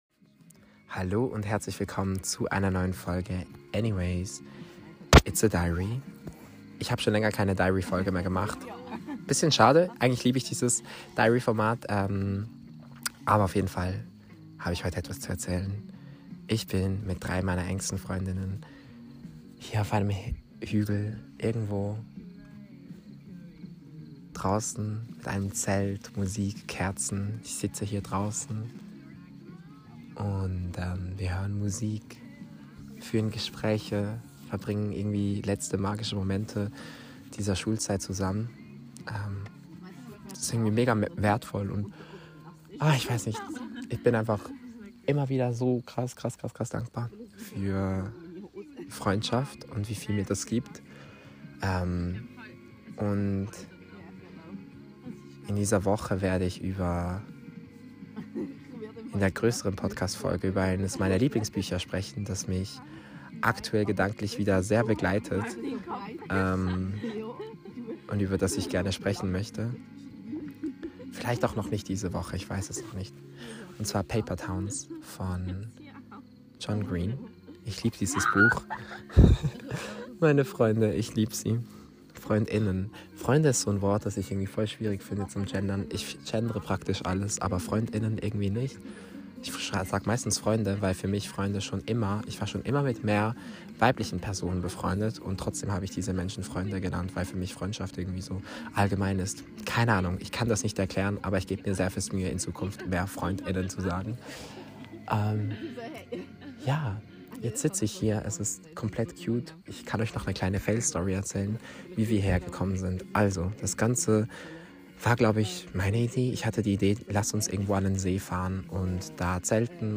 In dieser Tagebuchfolge nehme ich euch mit auf einen Campingtrip mit meinen Freundinnen. Auf Schweizerdeutsch gebe ich Serien- und Buchempfehlungen.